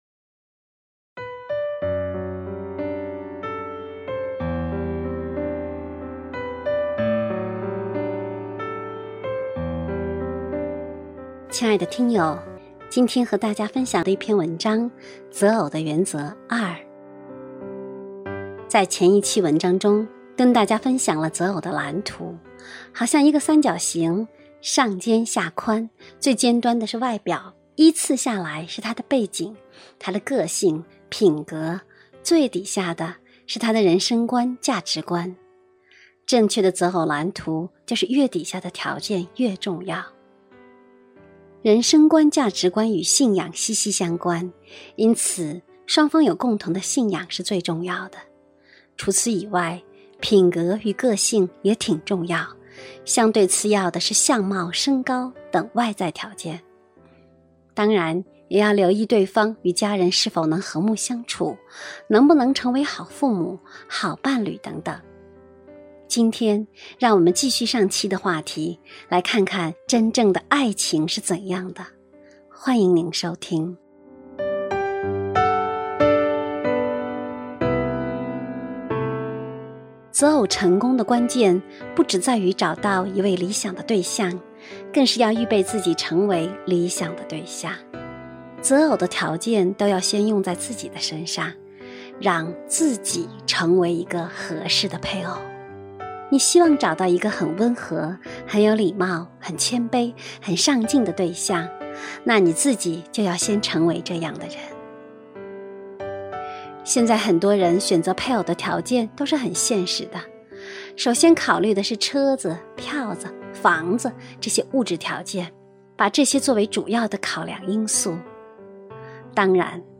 首页 > 有声书 > 婚姻家庭 > 单篇集锦 | 婚姻家庭 | 有声书 > 择偶的原则（二·）